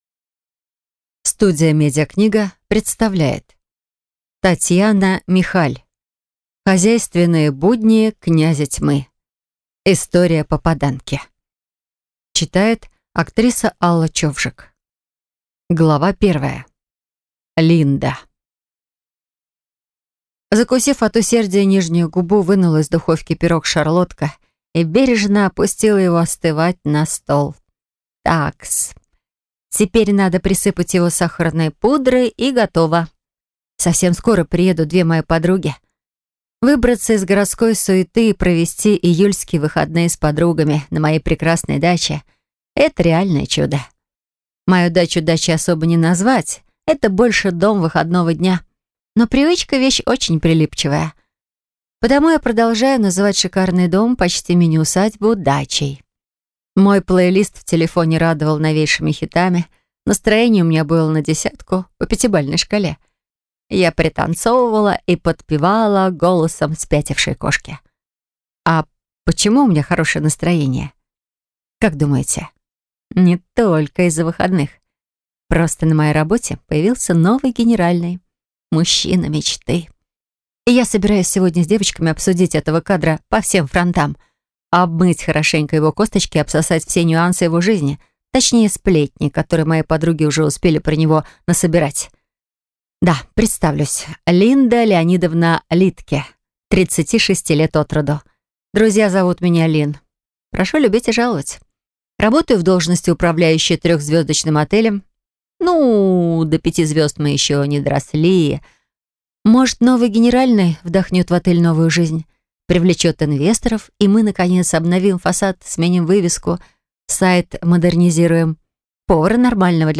Аудиокнига Хозяйственные будни Князя Тьмы. История попаданки. Книга 1 | Библиотека аудиокниг